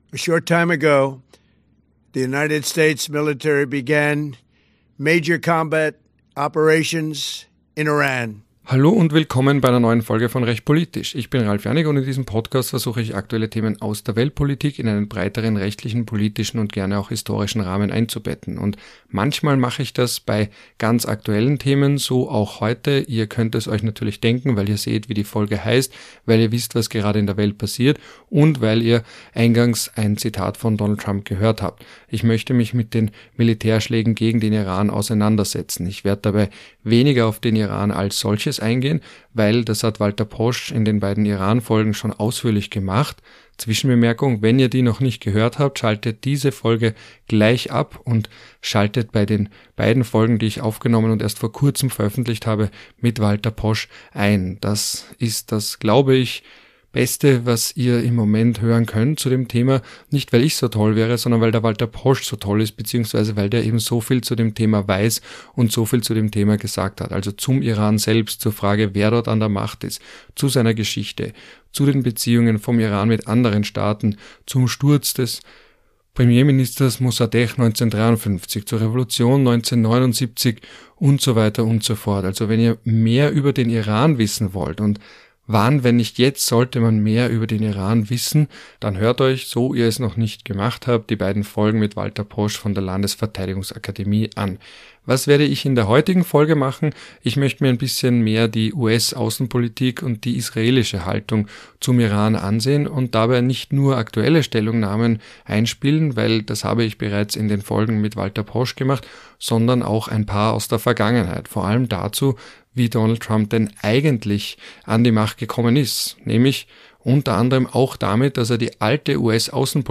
Wohl aber könnte es im MAGA-Lager (man denke vor allem an Tulsi Gabbard, von der ich auch Zitate einspiele) zu Zerwürfnissen führen.